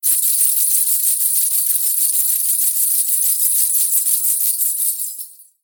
Danza árabe, bailarina haciendo el movimiento twist 03
continuo
moneda
Sonidos: Acciones humanas